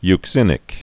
(yk-sĭnĭk)